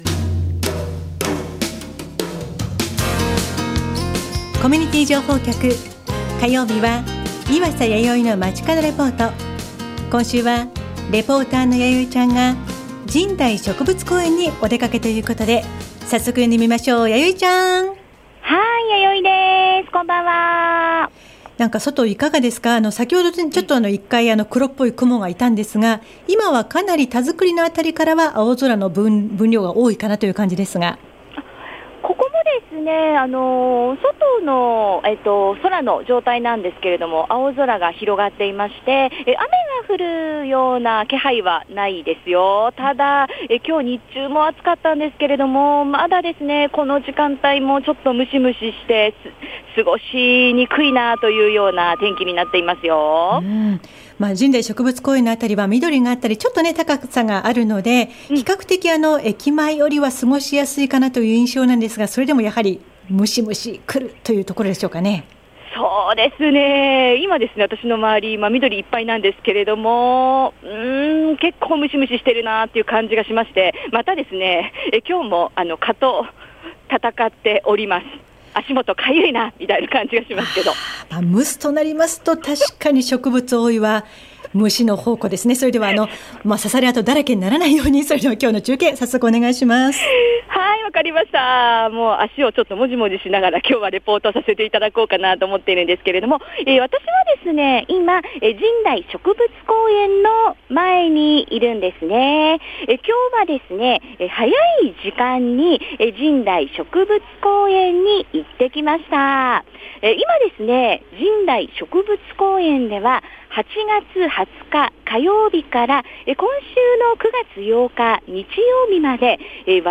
ラベンダークルーズ　街角レポート
今週は、神代植物公園に行ってきました～！